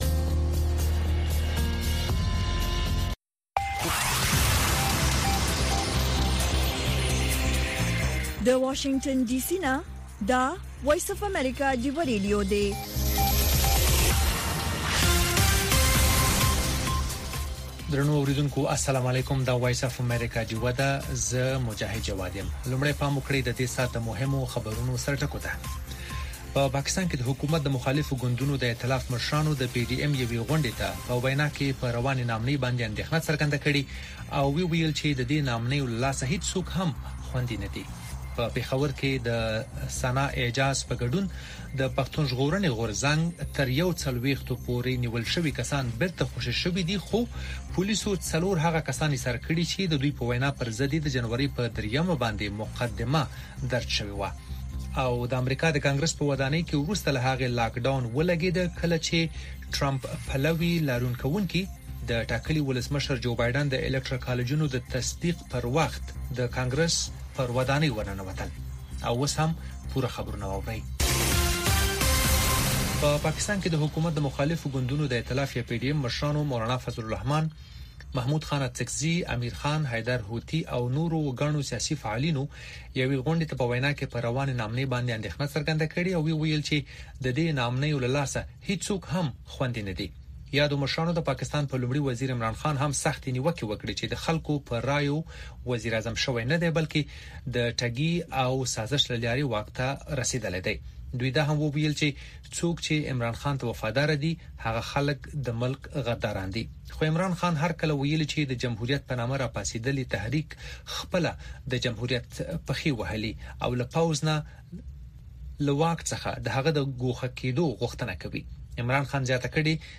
د وی او اې ډيوه راډيو سهرنې خبرونه چالان کړئ اؤ د ورځې دمهمو تازه خبرونو سرليکونه واورئ.